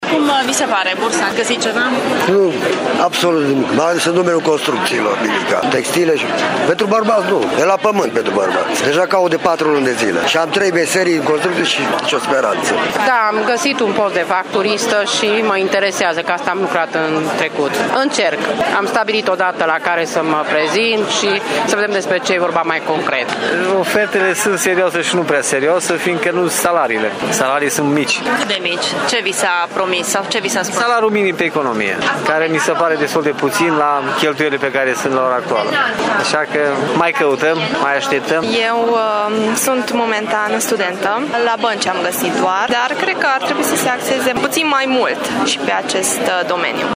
Oamenii sunt, însă, nemulțumiți de oferta limitată și slab retribuită: